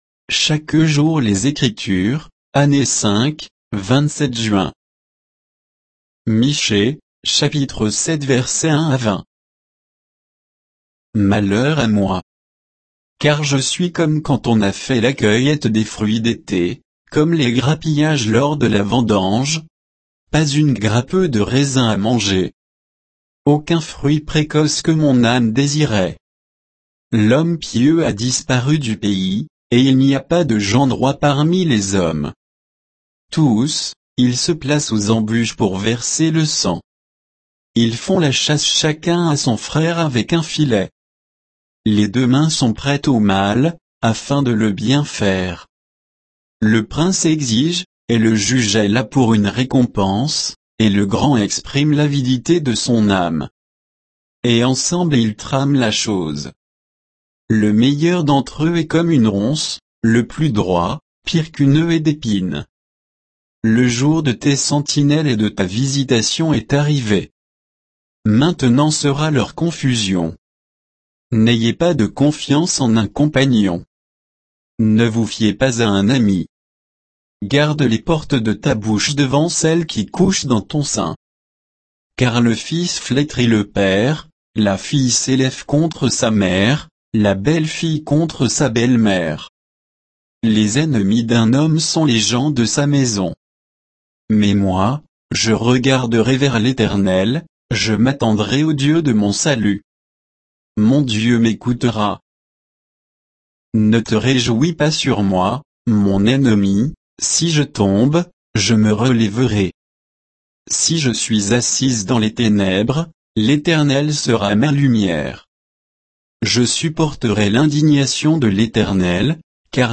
Méditation quoditienne de Chaque jour les Écritures sur Michée 7, 1 à 20